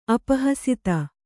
♪ apahasita